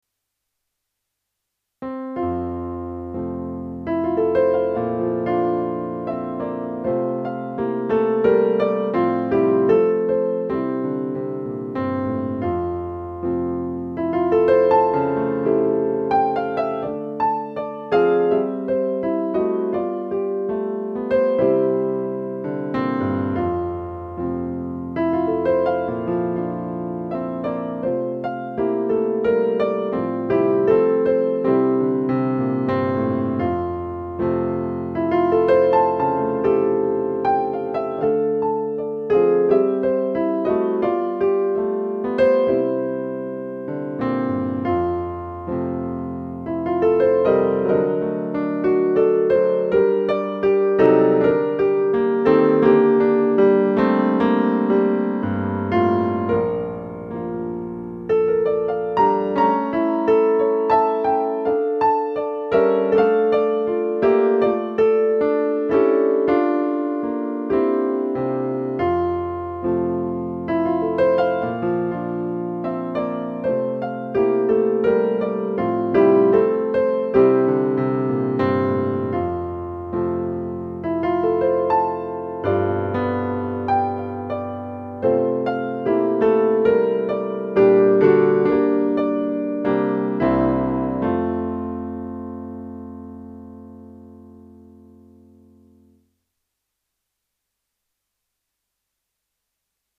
私の今回の音源は、直前までロックな曲を練習していたせいか、ロックな演奏、ロック・ミュージシャンの弾くクラシック、という趣きになっている気がする（ちょっと骨ばってるかな、という意味で）。